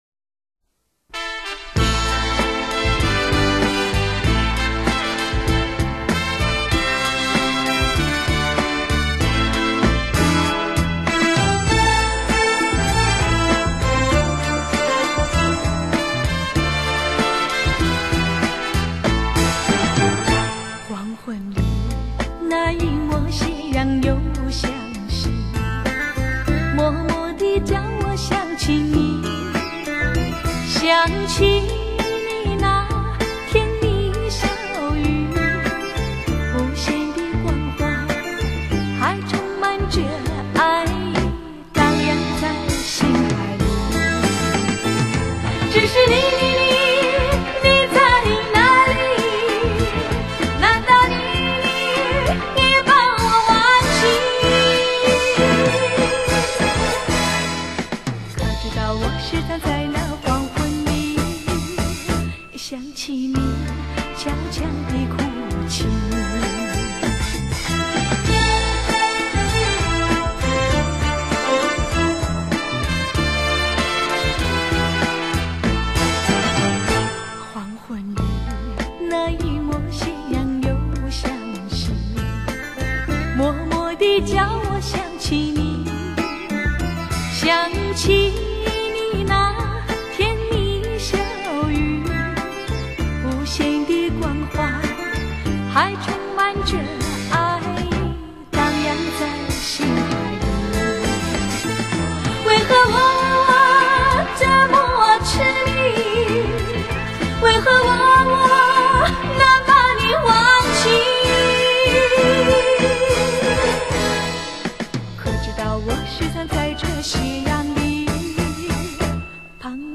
歌唱生涯首次大型现场演唱会
资料珍贵拥有30年历史，影音恐未臻理想，